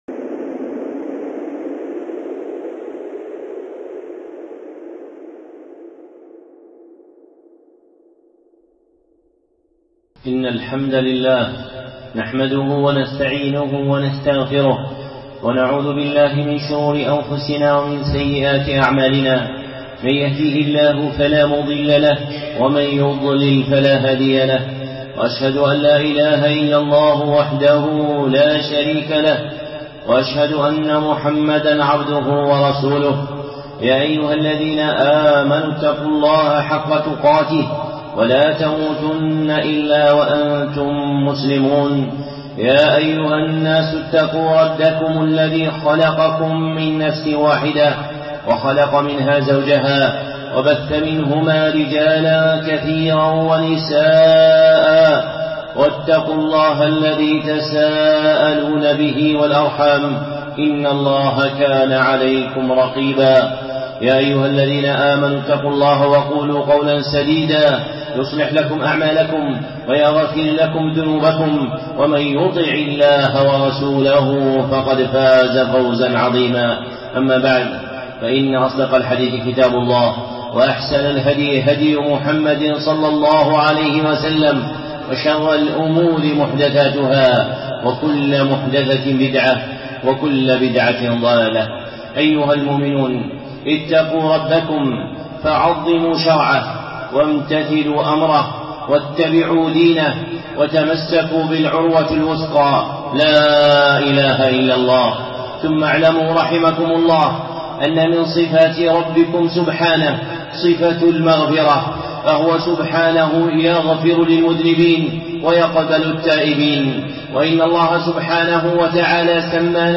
خطبة (من أبواب المغفرة)